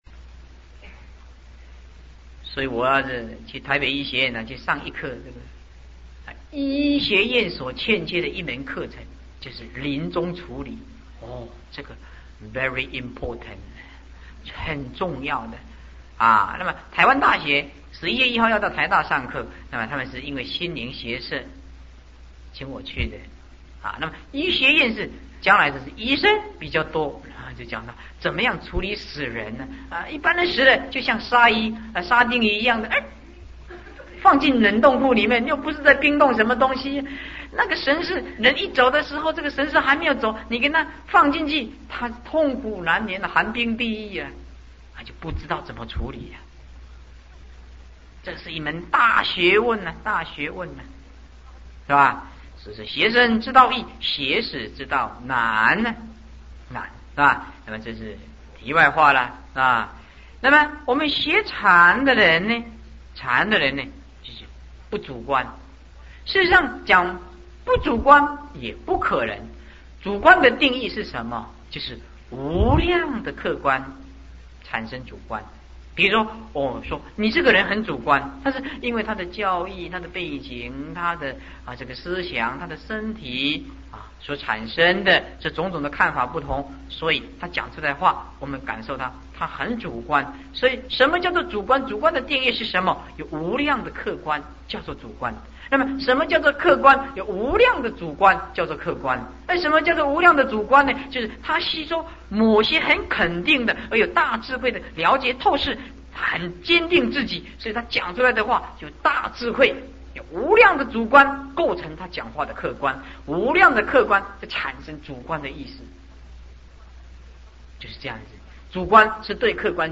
佛學講座